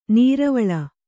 ♪ nīravaḷa